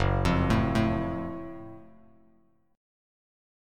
F#mM7 Chord